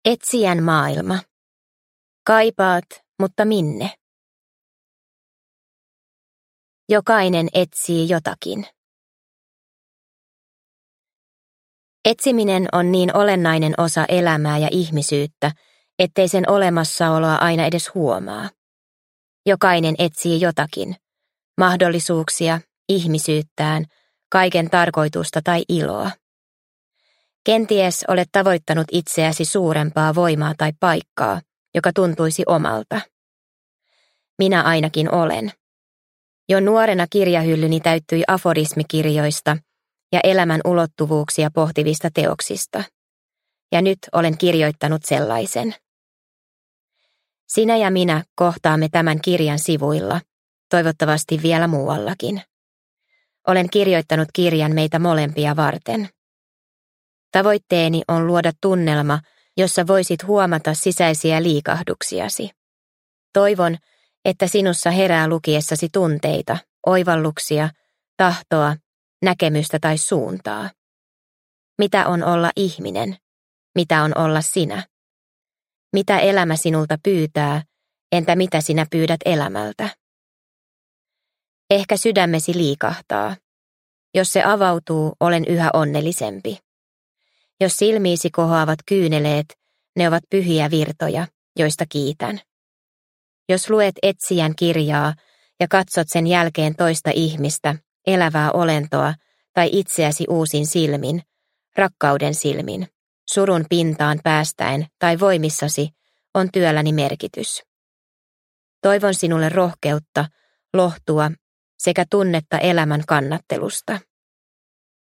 Etsijän kirja – Ljudbok – Laddas ner